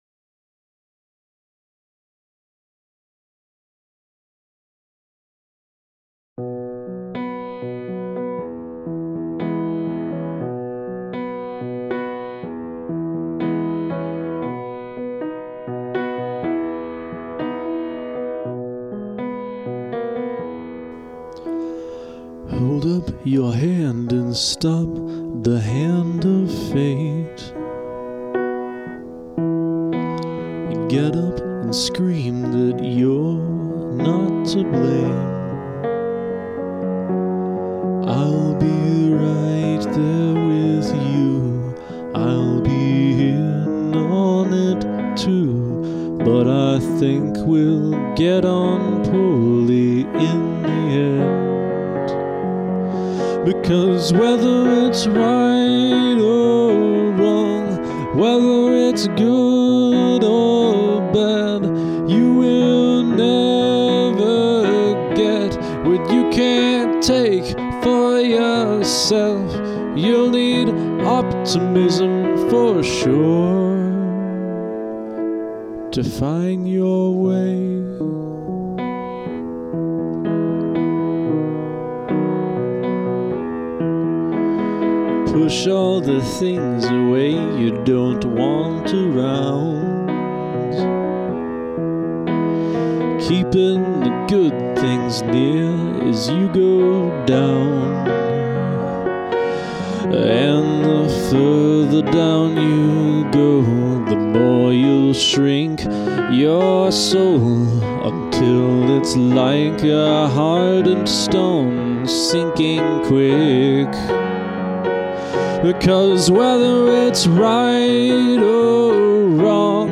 It's a long song with just piano and vocals
Also, leaving the microphone picking up my abrupt keyboard chord at the end was a good plan.
Really like this,especially guitar interlude.